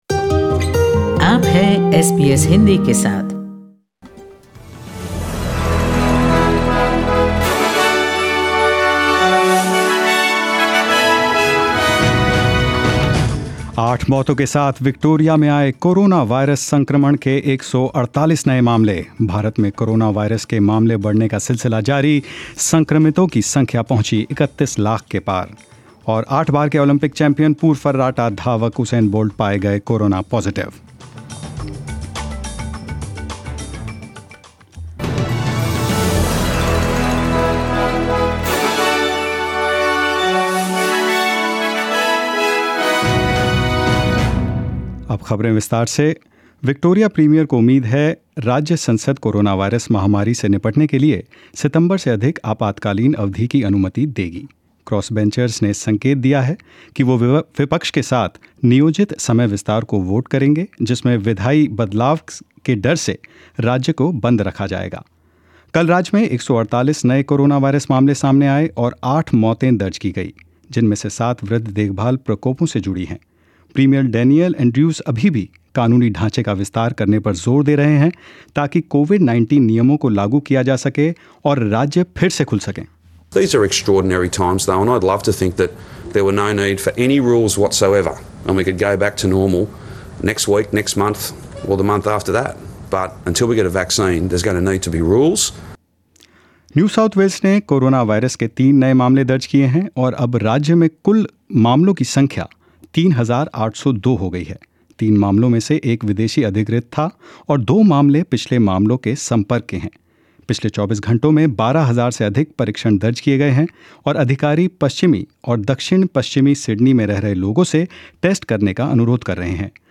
Hindi News 25th August 2020